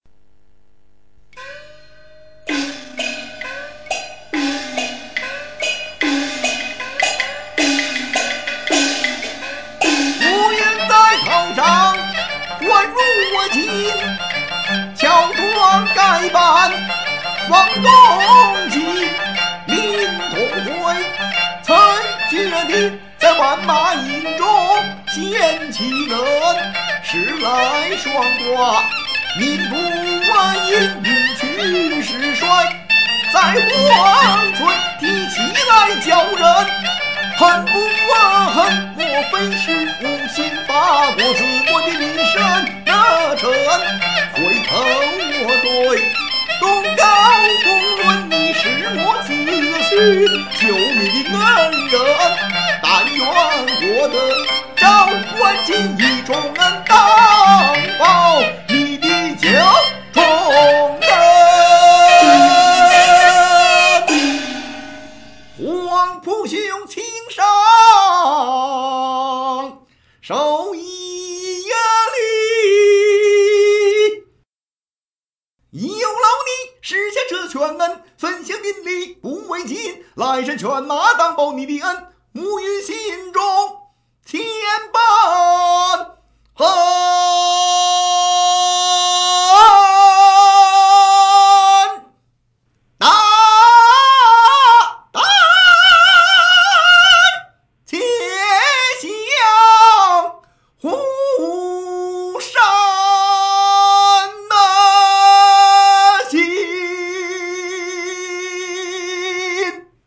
京剧《文昭关》--伍员在头上换儒巾